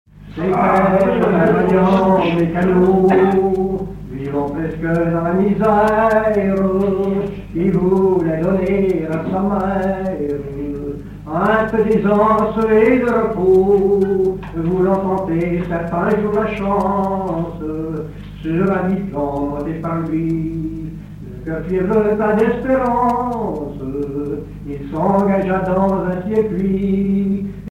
Genre strophique
Chansons d'un banquet
Pièce musicale inédite